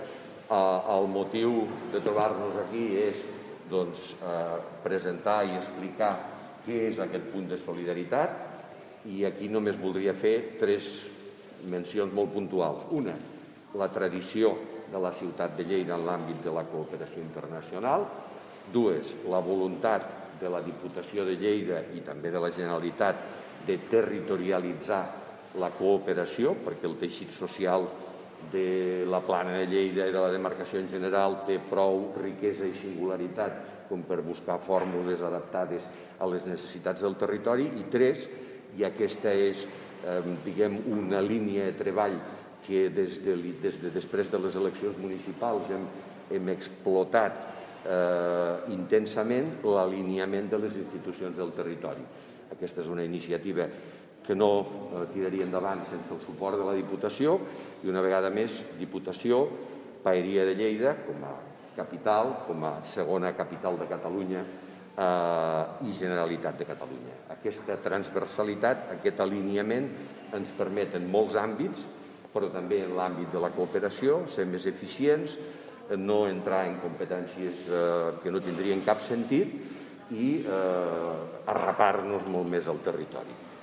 tall-de-veu-de-miquel-pueyo-sobre-el-punt-de-solidaritat